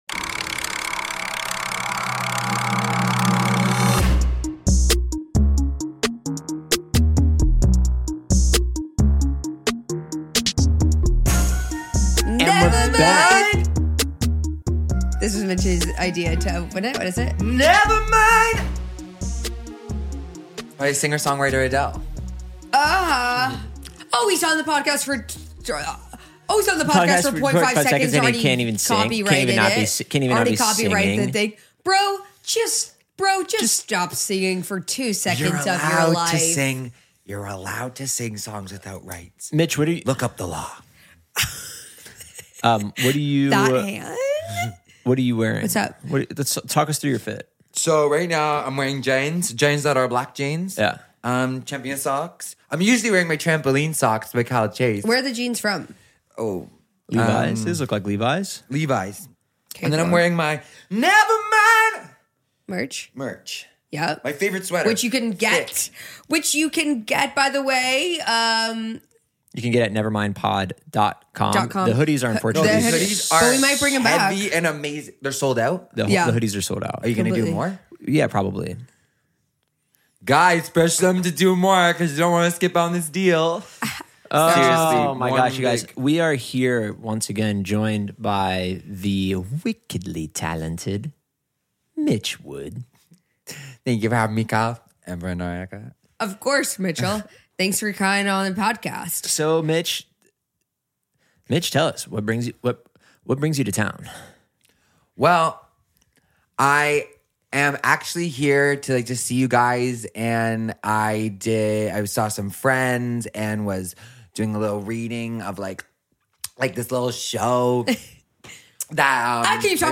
They get into their experience at "the brutalist", memoir book titles, and of course some musical improvisation.